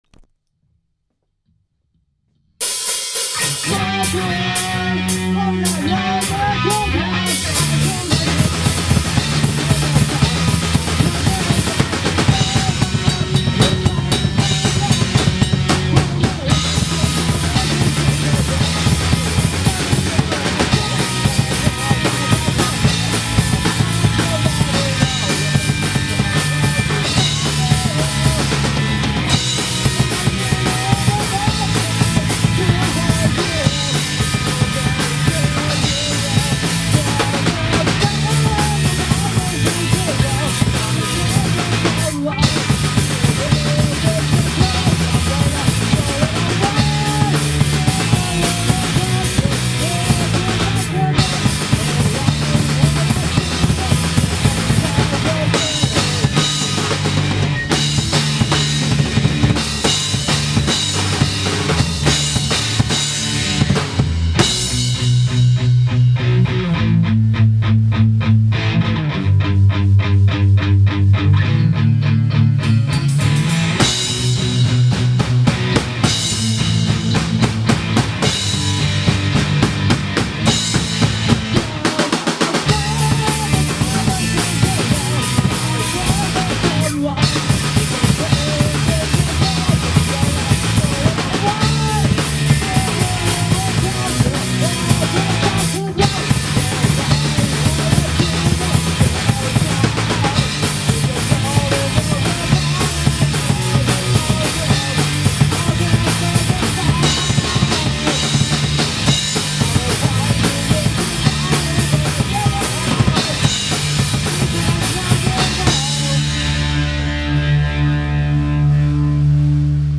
メロコアなのかパンクなのか、3ピースバンド
スタジオ一発録りのDEMO音源と、今までのLIVE動画。